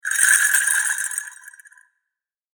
神社の本坪鈴2.mp3